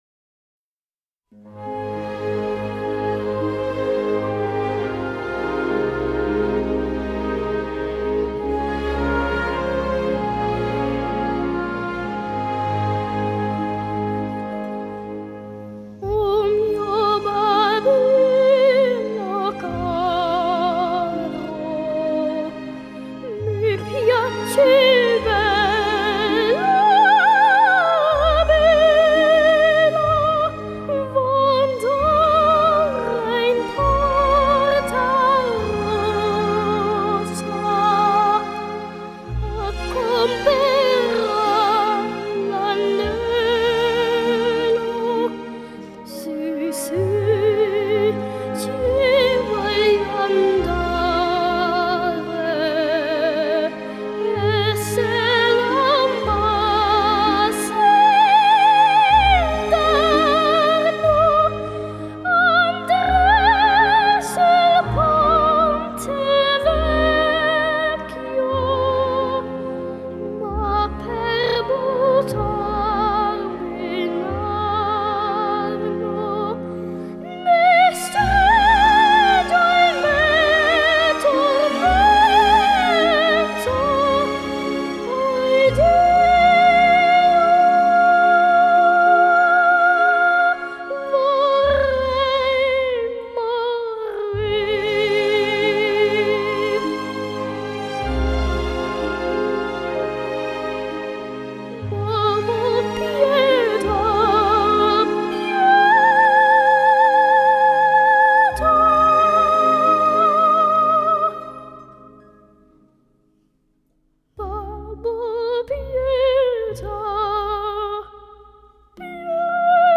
Genre: Classical, Opera